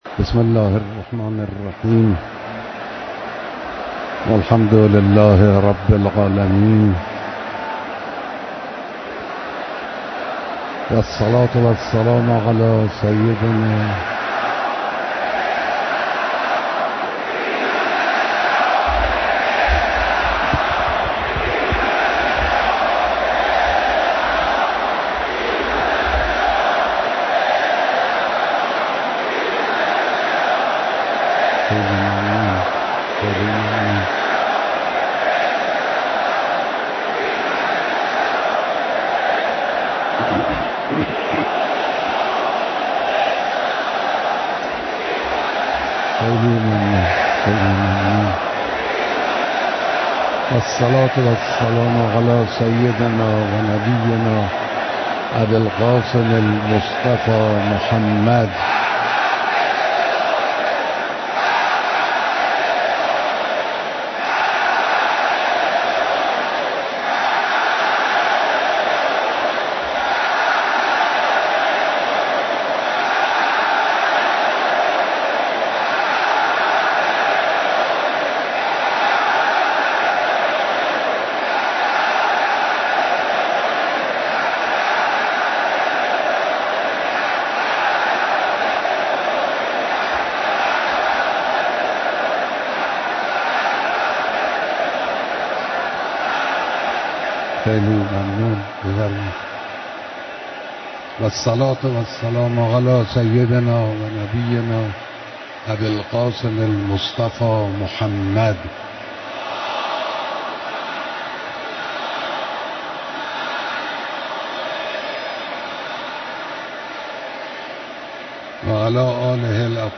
بیانات در اجتماع عظیم مردم در حرم مطهر امام راحل